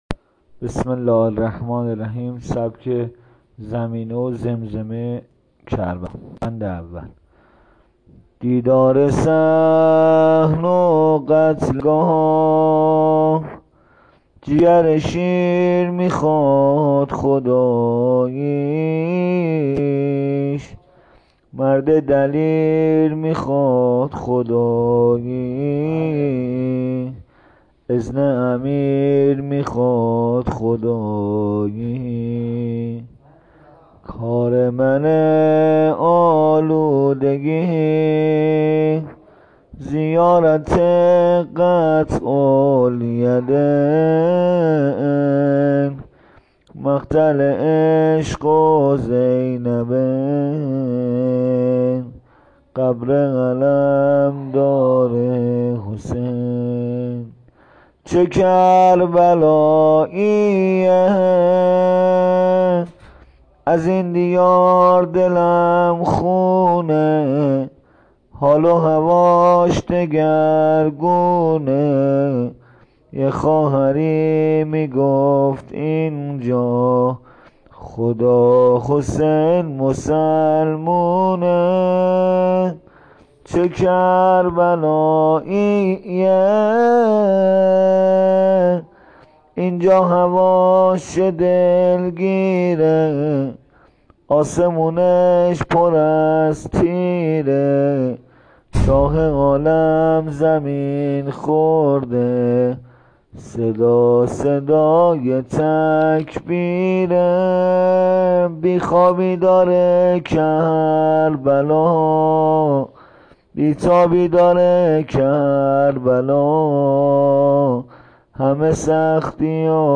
زمینه ی سینه زنی شب اول محرم..